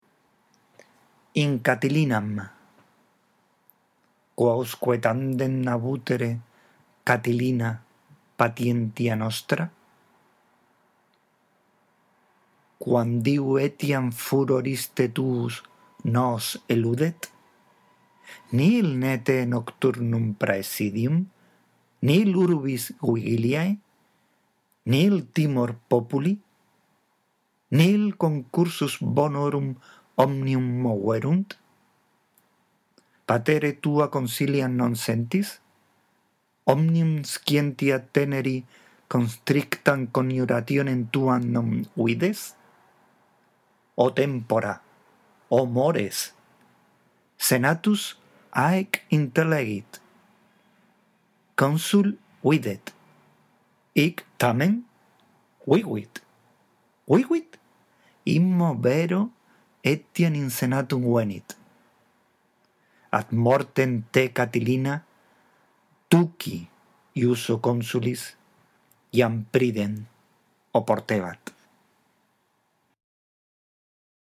2.ª actividad: lectio, la lectura
La audición de este archivo te ayudará en la mejora de la lectura del latín.